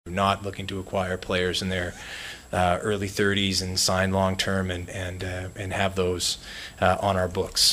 Penguins president of hockey operations Kyle Dubas took questions for over half an hour yesterday in his season-ending news conference, and while he is certain he has the team on the right track, he hinted it might be another year before fans see the sort of Penguins Stanley Cup-contending team they are used to.